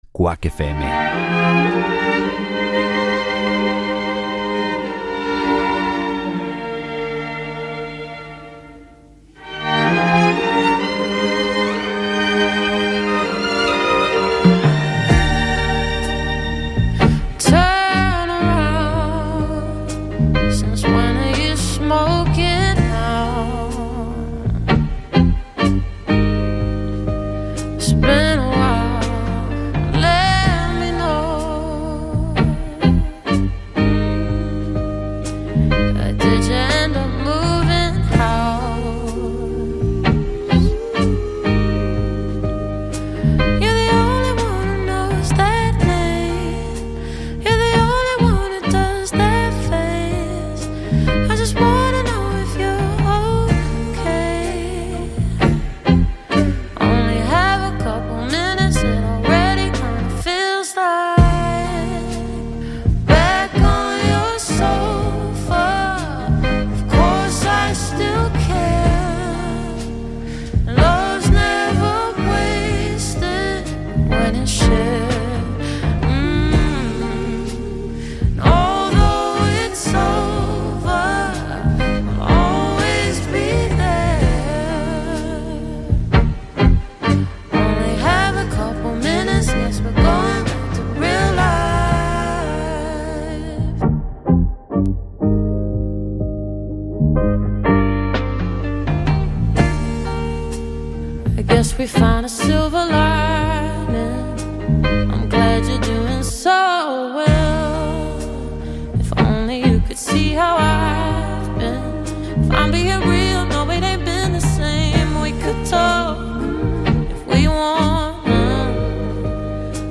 Todo iso misturado con boa música e un pouco de humor se o tema o permite. Cada martes ás 18 horas en directo.
Programa emitido cada mércores de 19:00 a 20:00 horas.